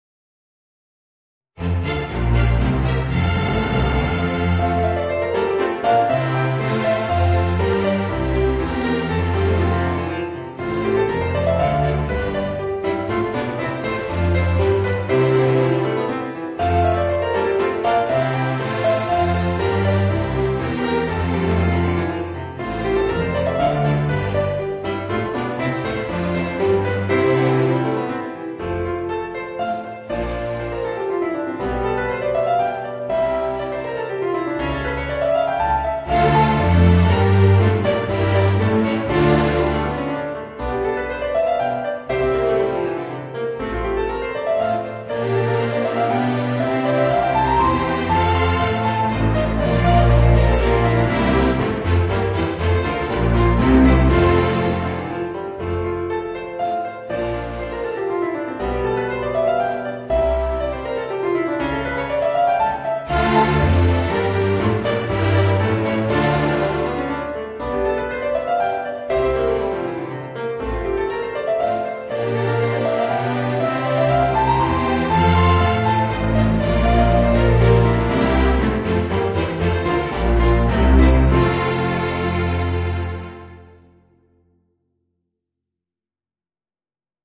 Voicing: Piano Solo